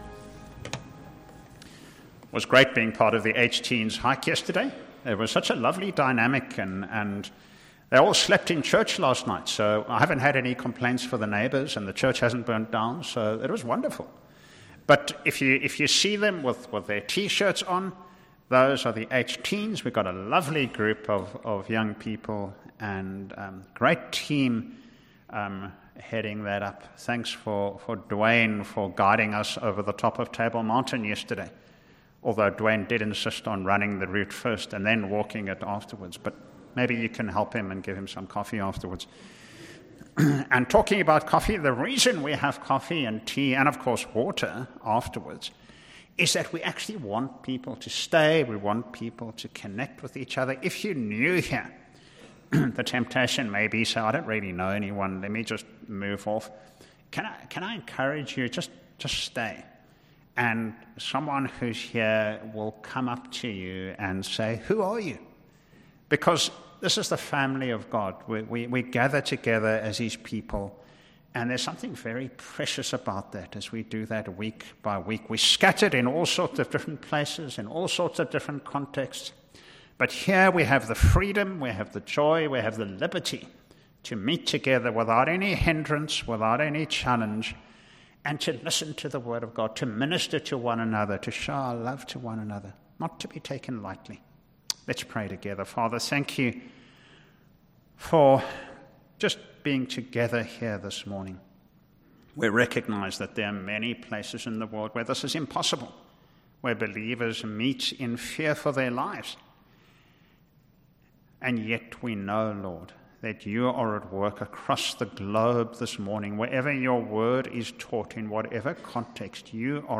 2025 The rich man and Lazarus Preacher